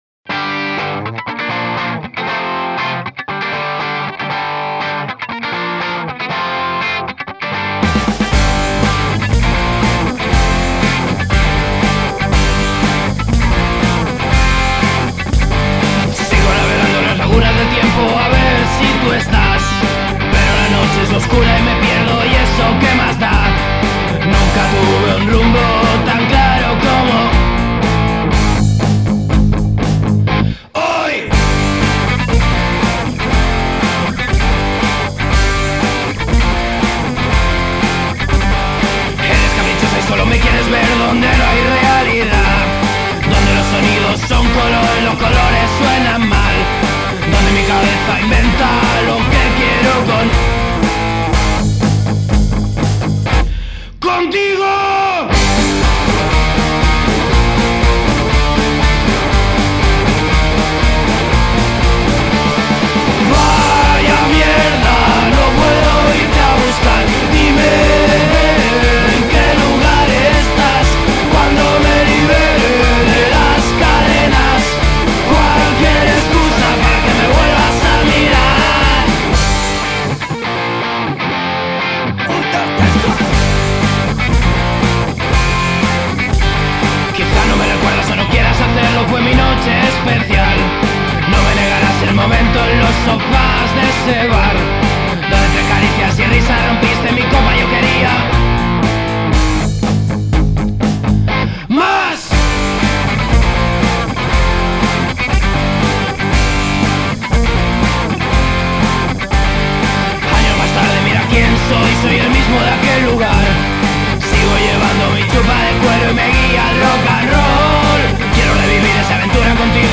Punk-Rock